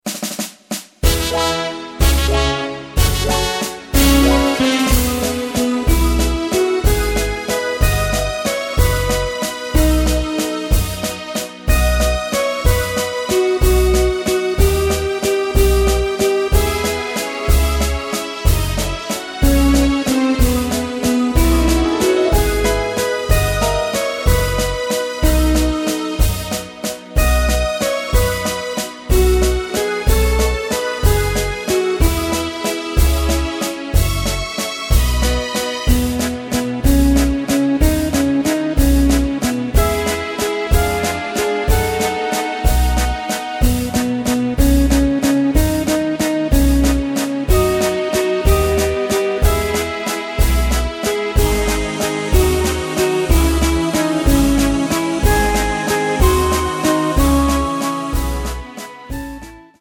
Takt:          3/4
Tempo:         186.00
Tonart:            E
Wiener Walzer!
Playback mp3 Demo